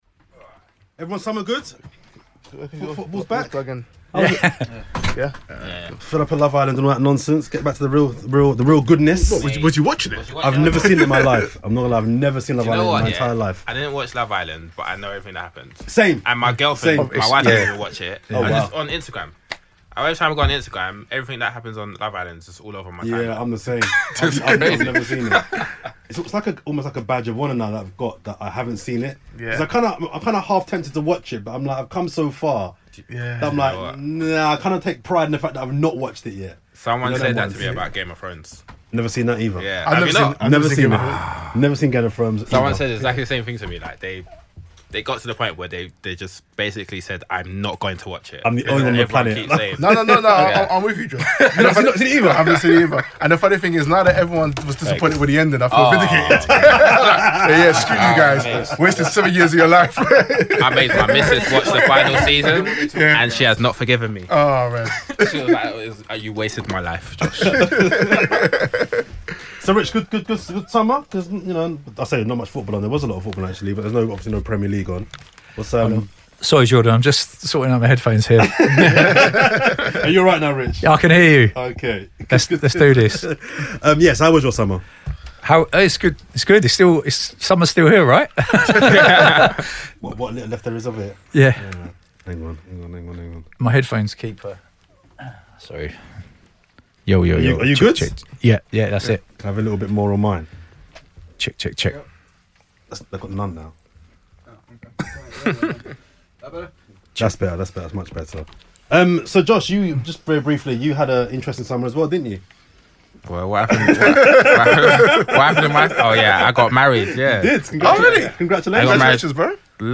This week on the show, with transfer deadline day looming, we speak to a Football Agent.